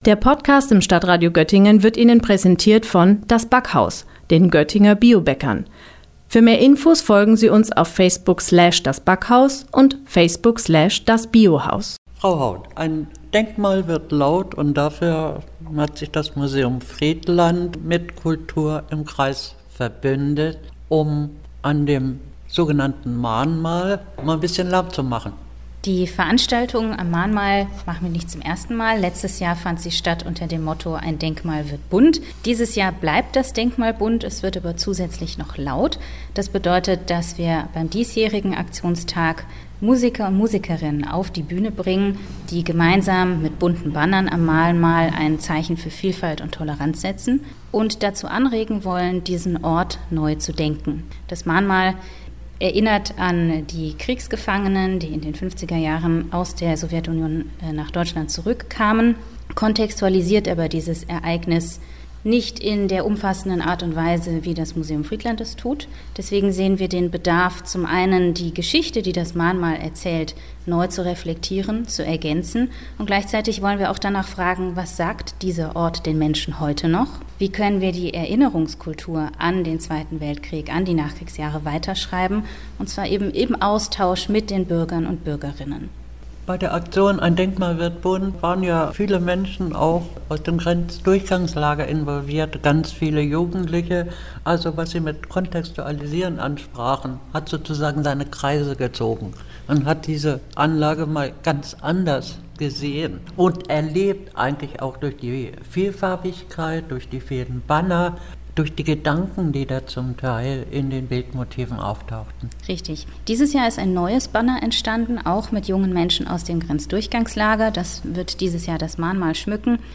Beiträge > Mit „Kultur im Kreis“ nach Friedland, wo ein Denkmal laut wird - StadtRadio Göttingen